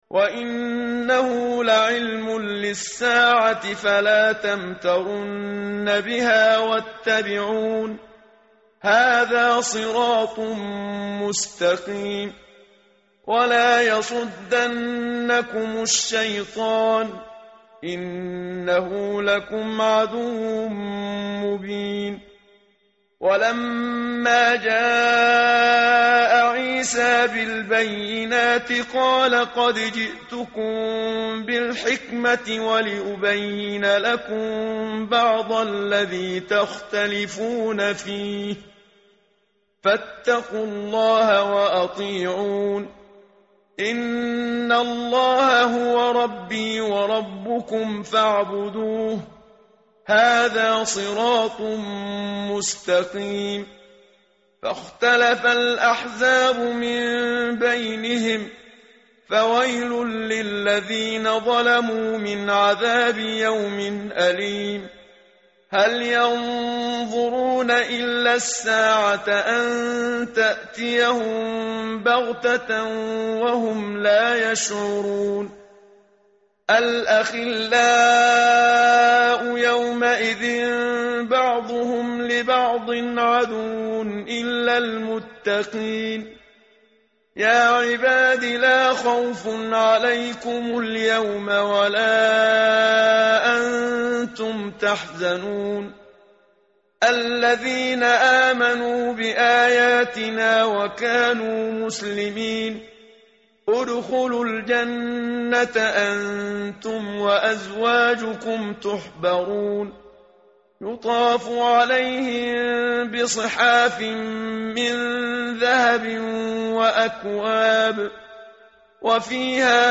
متن قرآن همراه باتلاوت قرآن و ترجمه
tartil_menshavi_page_494.mp3